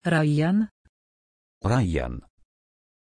Aussprache von Rayyan
pronunciation-rayyan-pl.mp3